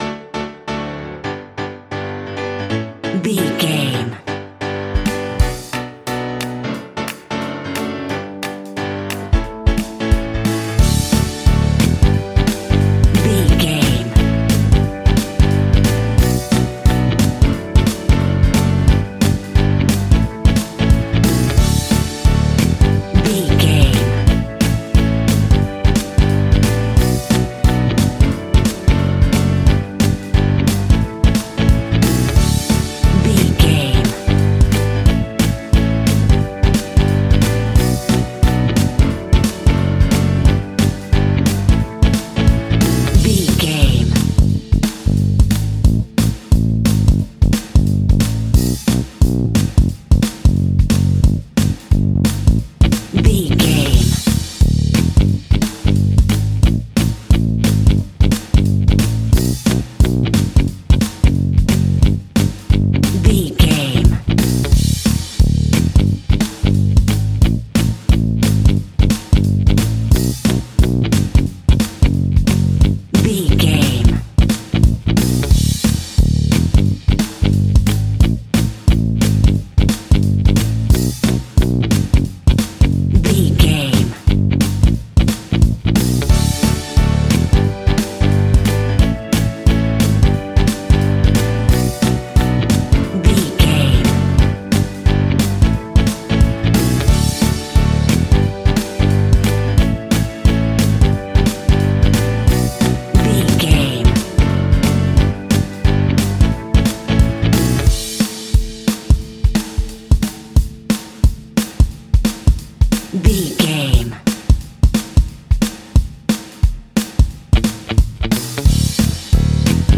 Uplifting
Ionian/Major
D
pop rock
indie pop
fun
energetic
acoustic guitars
drums
bass guitar
electric guitar
piano
organ